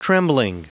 Prononciation du mot trembling en anglais (fichier audio)
Prononciation du mot : trembling
trembling.wav